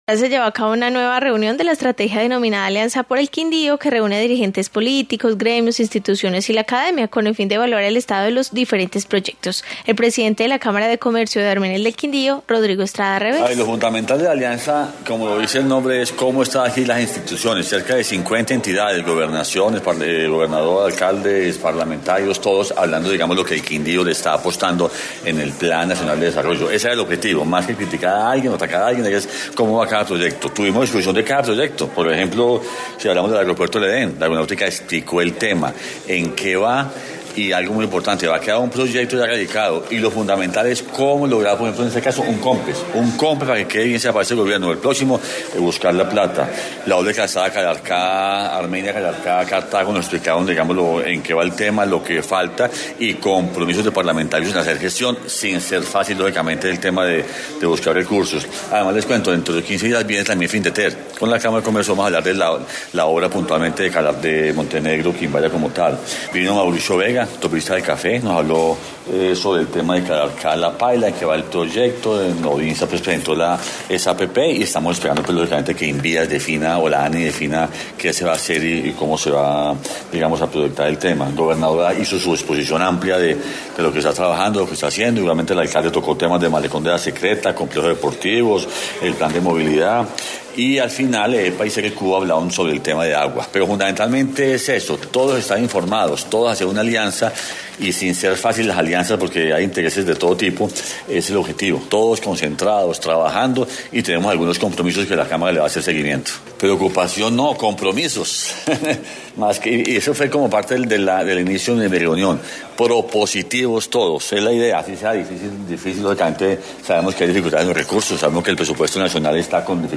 Informe sobre alianza por el Quindío